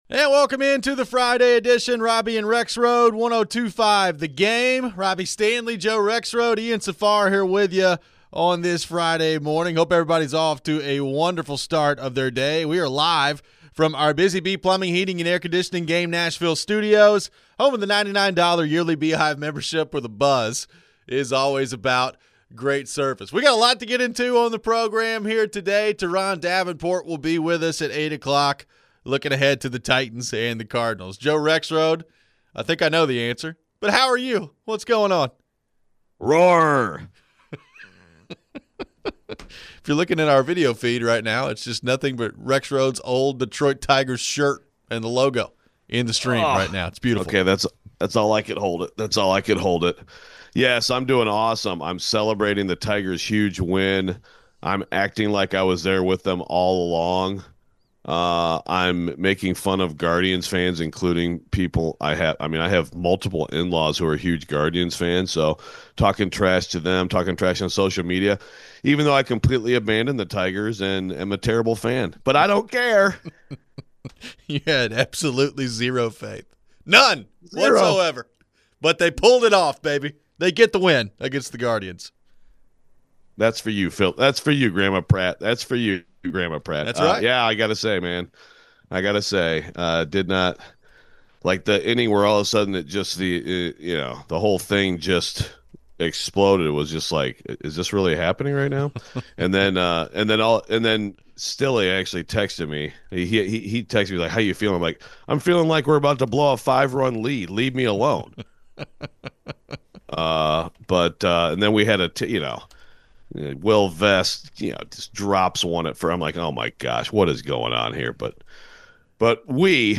We ask the question, which team makes you an irrational fan? We take your phones. We get into some college football.